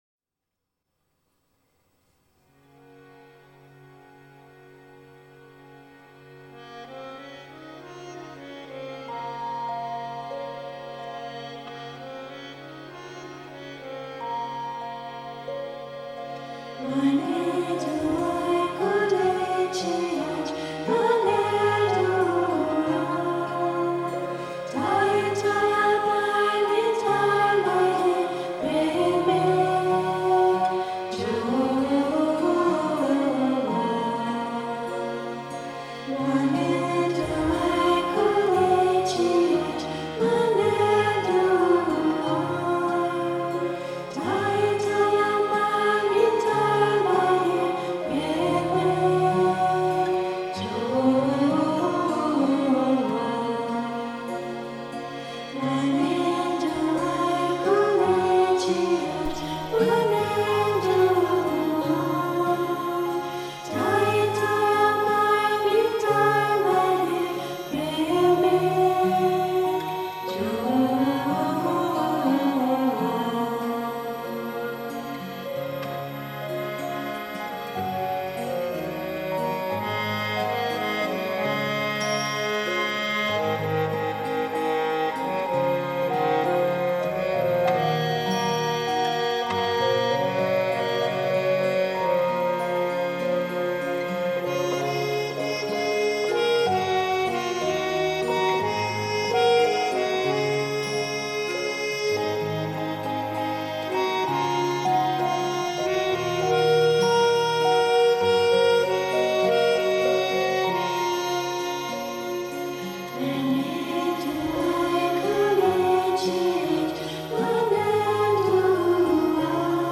instrumental and vocal ensemble
soulful arrangements
featuring harmonium, glockenspiel, guitar and percussion.